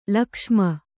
♪ lakṣma